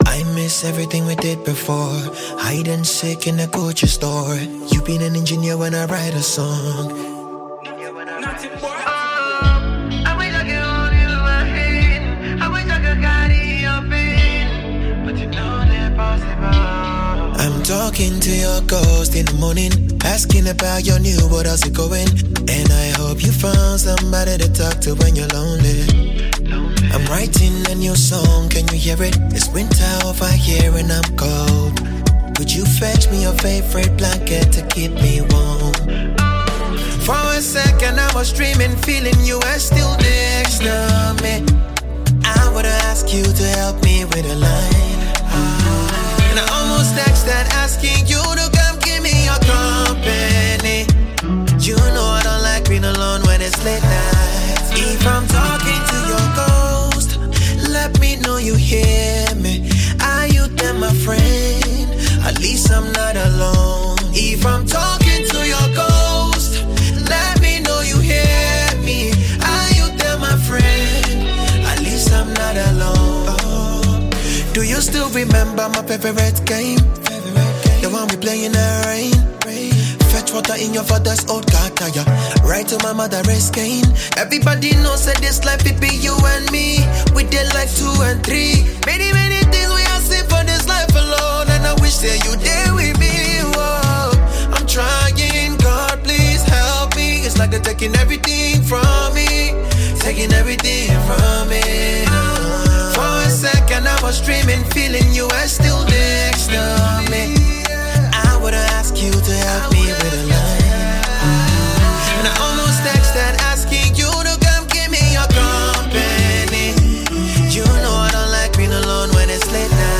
Ghanaian Afrobeats sensation
heartfelt new single